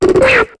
Cri de Kraknoix dans Pokémon HOME.